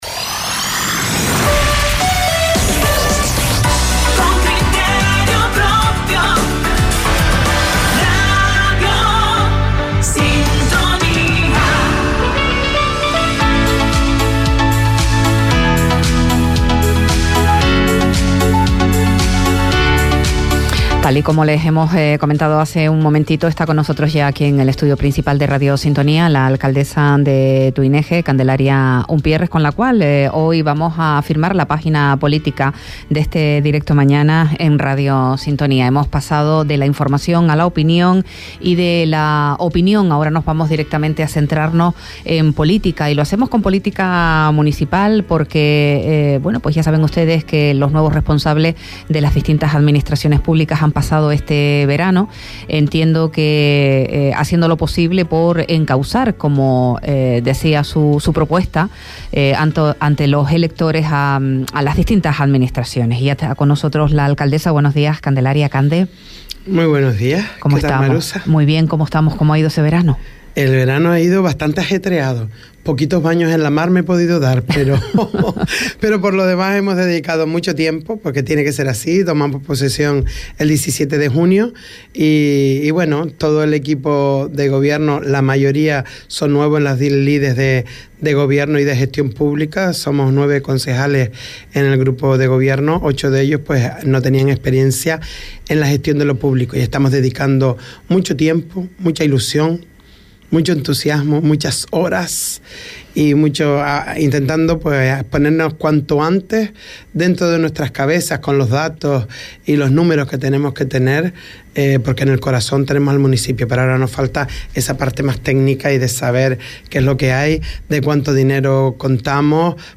Entrevista a Candelaria Umpiérrez, alcaldesa de Tuineje – 21.09.23
Entrevistas